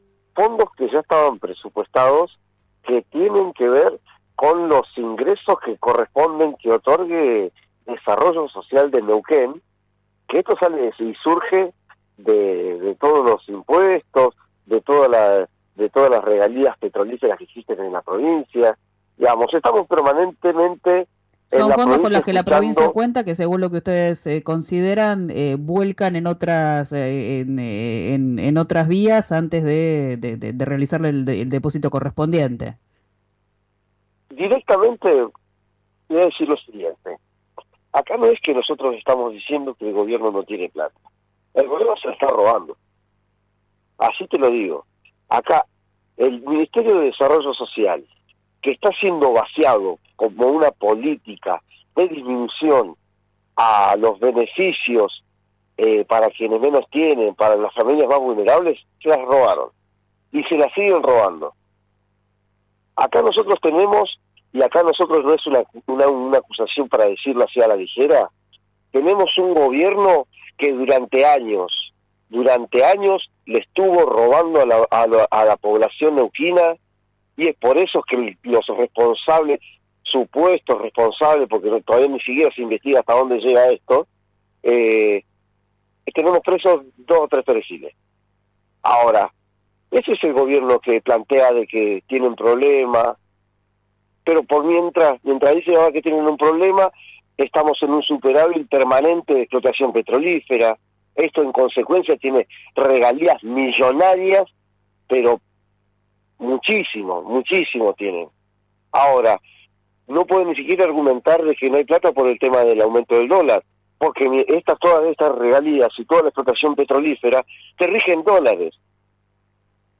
habló con RÍO NEGRO RADIO y fue enfático